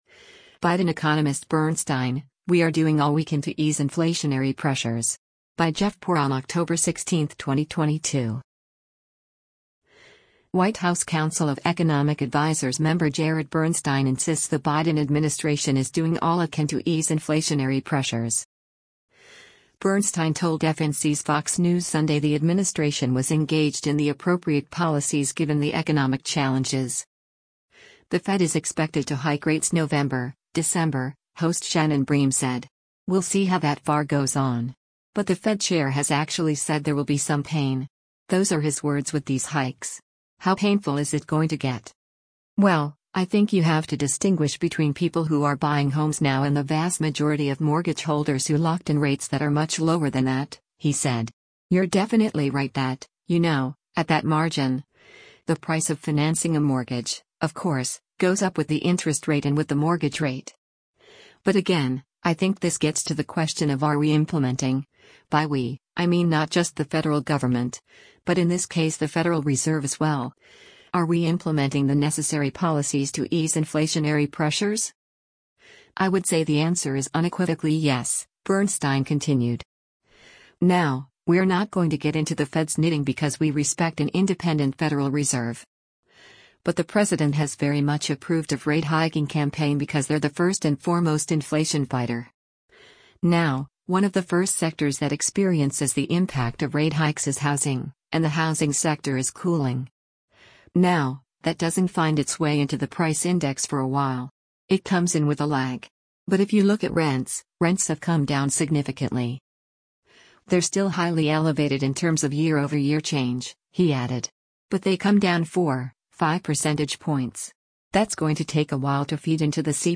Bernstein told FNC’s “Fox News Sunday” the administration was “engaged in the appropriate policies” given the economic challenges.